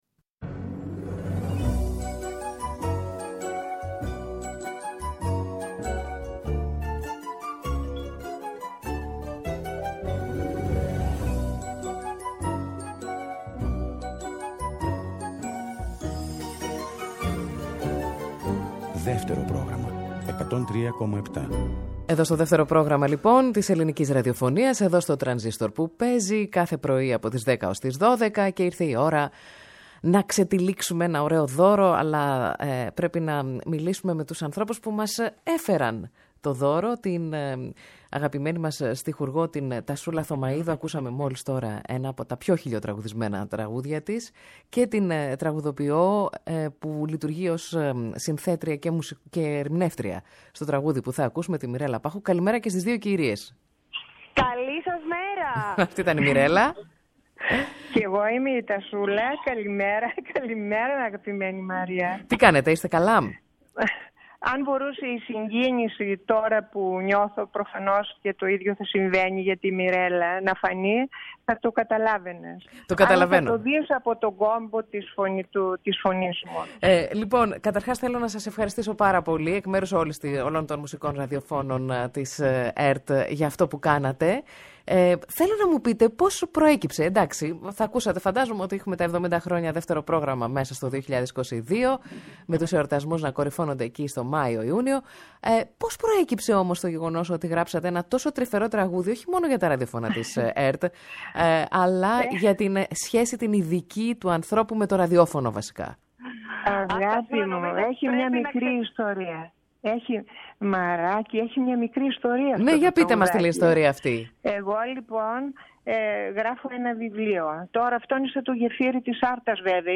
Οι δημιουργοί του τραγουδιού μας μιλήσαν για την καλλιτεχνική συνάντησή τους και φυσικά ακούσαμε το τραγούδι.
Συνεντεύξεις